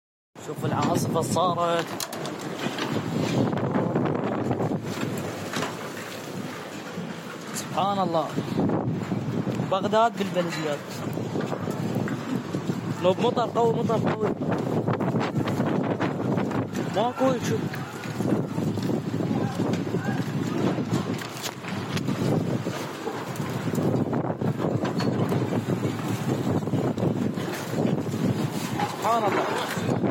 شاهد عاصفه تضرب بغداد البلديات sound effects free download